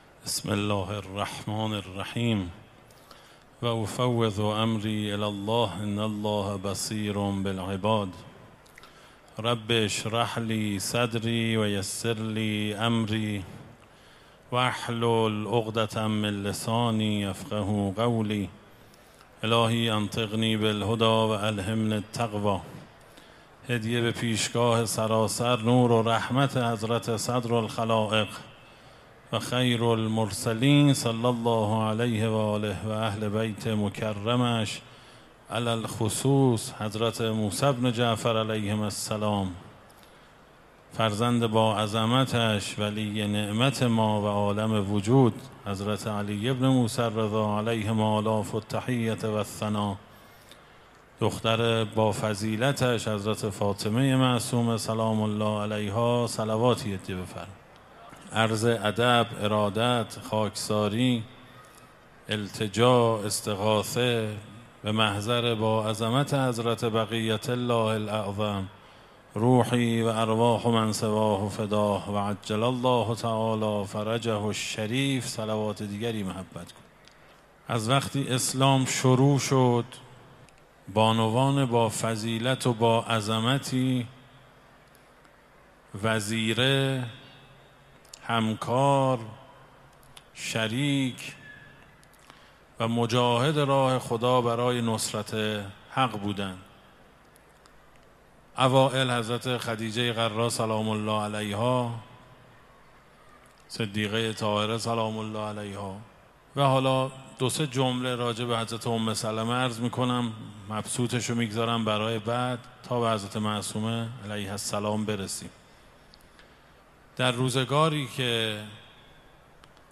در حرم مطهّر حضرت فاطمه معصومه سلام الله علیها
سخنرانی